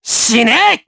Neraxis-Vox_Skill1_jp.wav